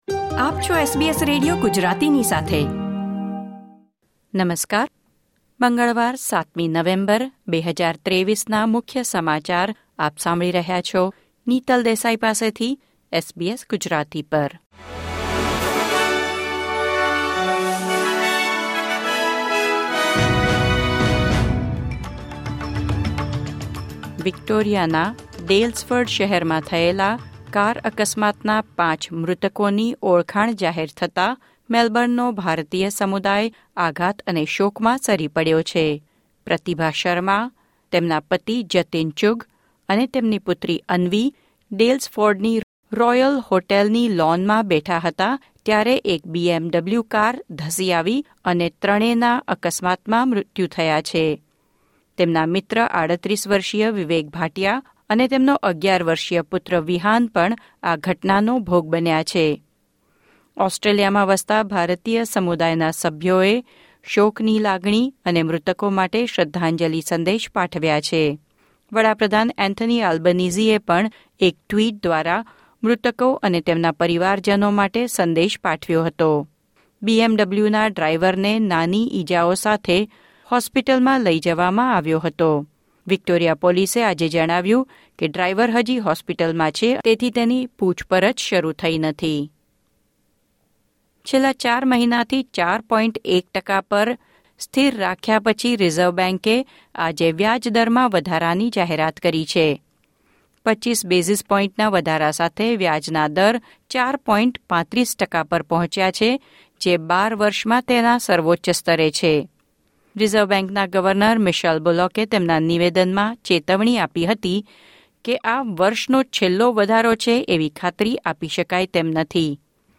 SBS Gujarati News Bulletin 7 November 2023